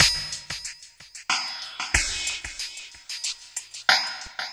7-8 CHUGGI-R.wav